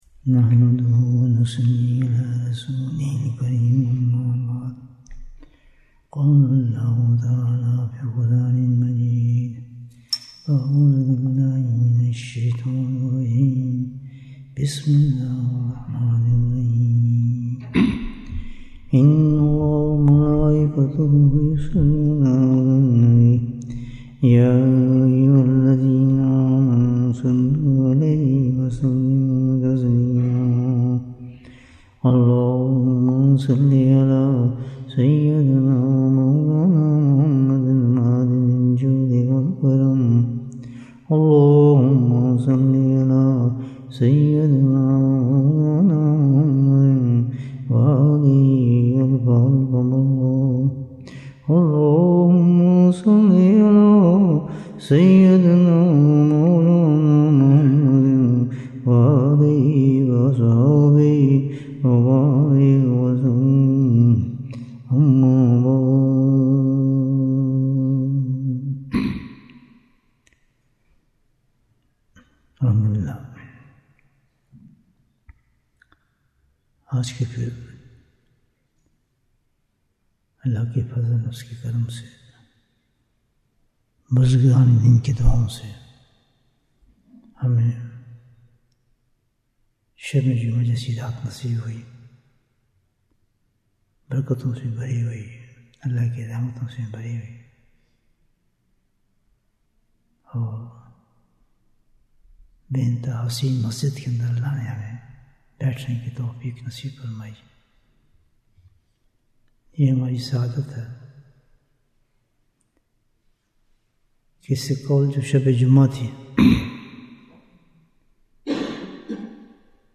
Bayan, 83 minutes 27th November, 2025 Click for English Download Audio Comments How Can We Die With Safety of Iman?